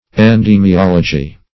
Search Result for " endemiology" : The Collaborative International Dictionary of English v.0.48: Endemiology \En*dem`i*ol"o*gy\, n. The science which treats of endemic affections.